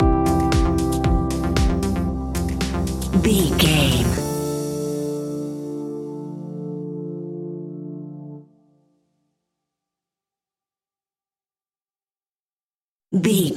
Aeolian/Minor
D
funky
groovy
uplifting
driving
energetic
drum machine
electric piano
synthesiser
electro house
funky house
synth leads
synth bass